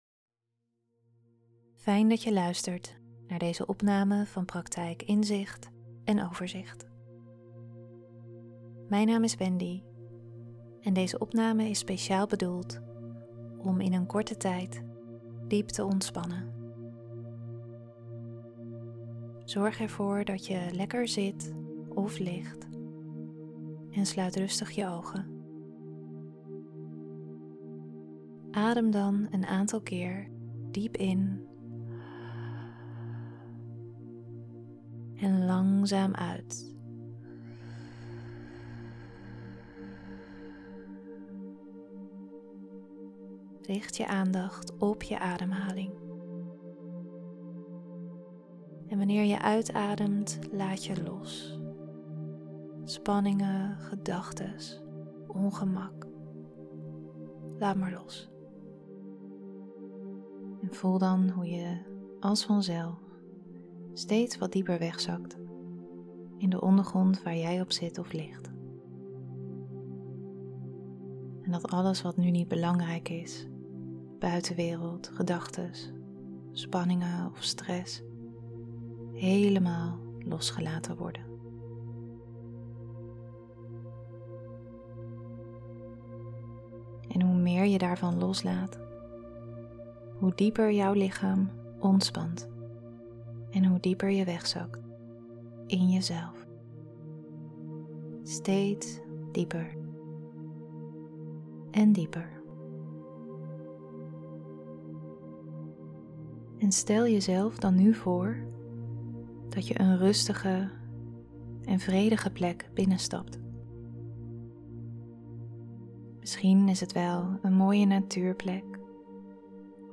Meditaties
7 Minuten Ontspanning Met Muziek Mp 3